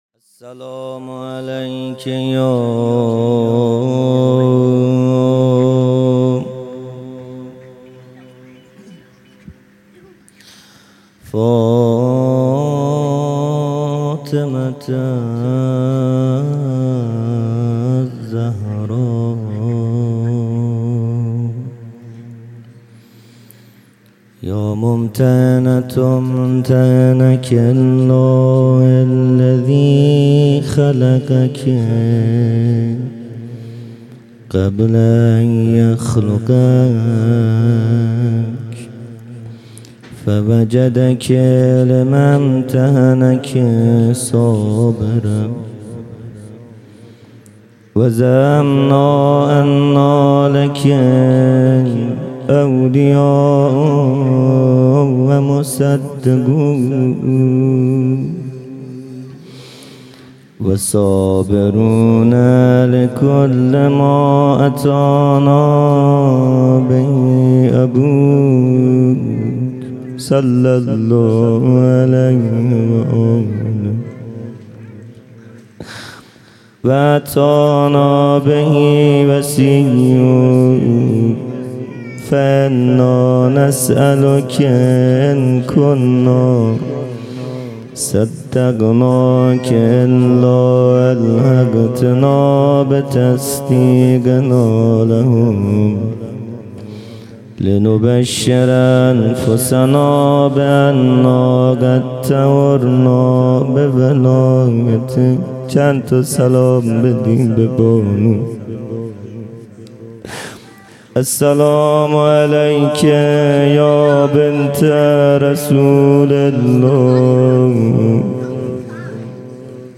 روضه | تا زنده ام برای شما گریه میکنم